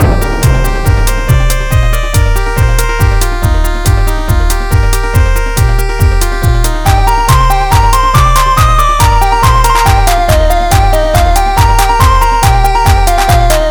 rockspin.wav